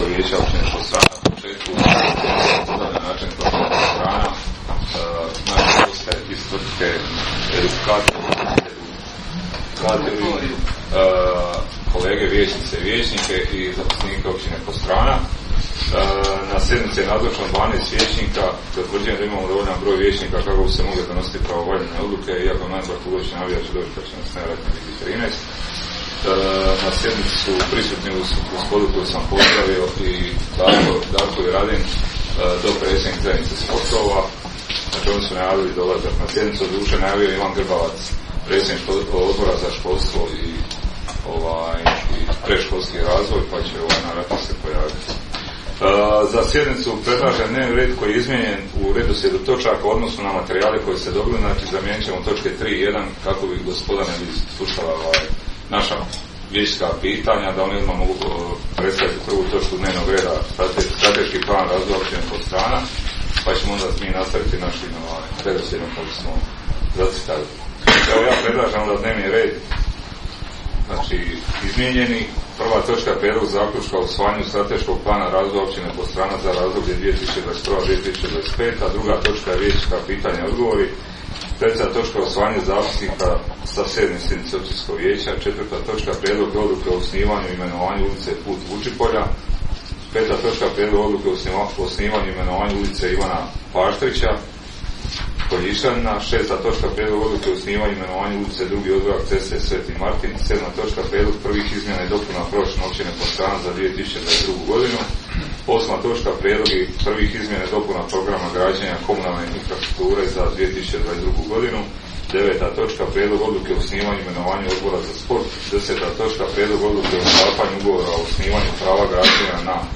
Sjednica će se održati dana 11. svibnja (srijeda) 2022. godine u 19,00 sati u vijećnici Općine Podstrana.